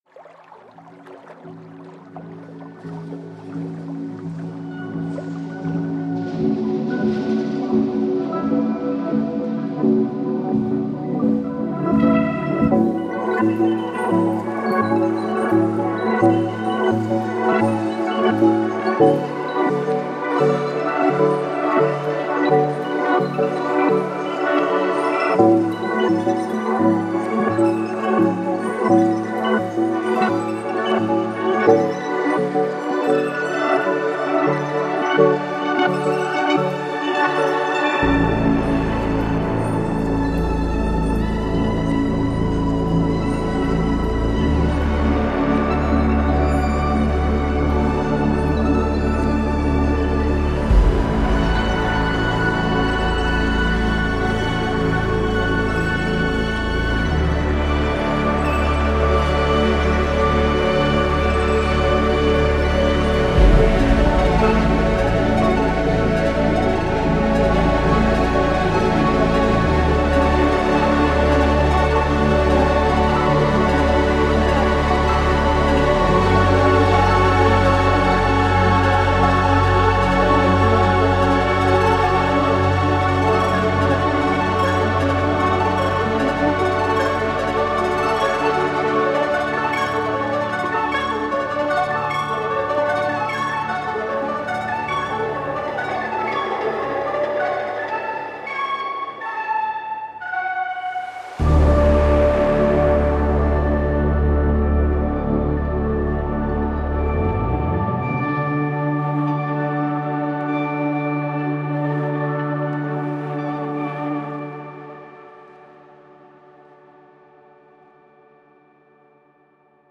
ThePhonoLoop Tapes.01 的特点是它使用了磁带机的模拟技术，给声音增加了一些暖色和噪音，让它们听起来更有质感和复古感。
ThePhonoLoop Tapes.01 是一个适合各种类型和风格的音乐制作的音色库，它提供了丰富多样的合成器声音，从温暖柔和的垫音、琴音、弦乐，到动感强烈的贝斯、铅音、打击乐3。